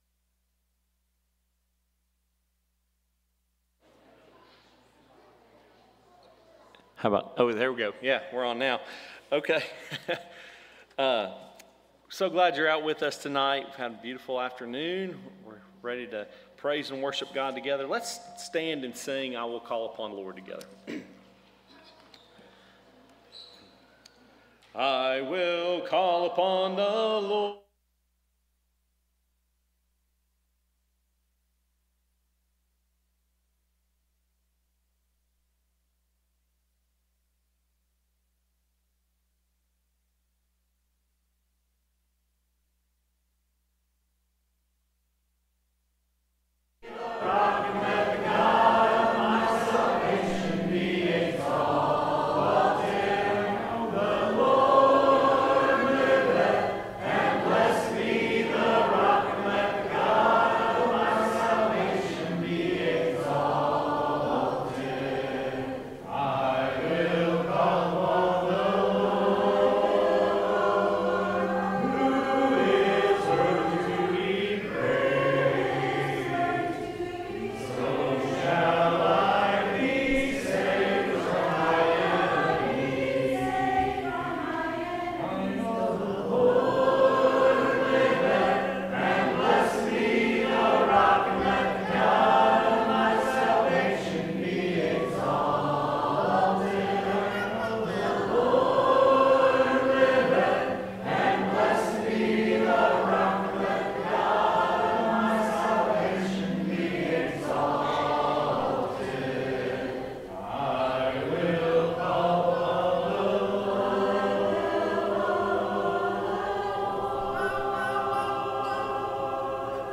Romans 12:14; English Standard Version Series: Sunday PM Service